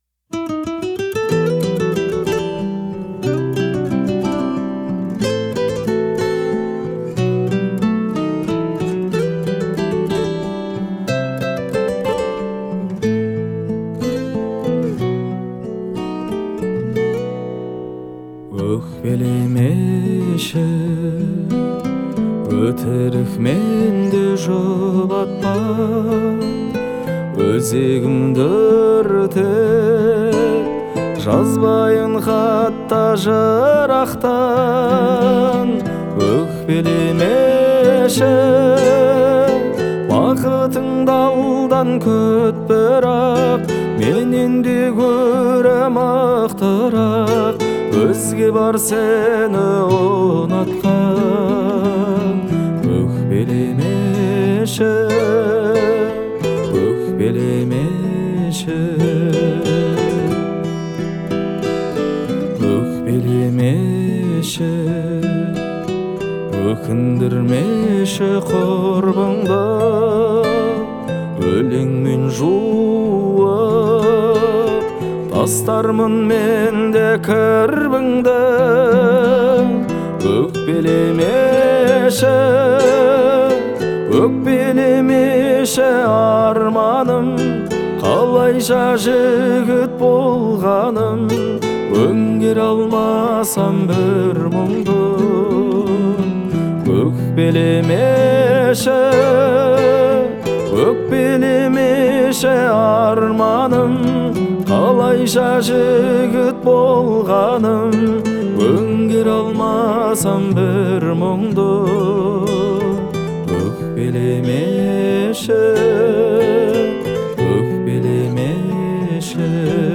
это трек в жанре казахского поп-фолка